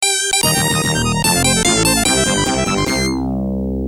FM音源の音の例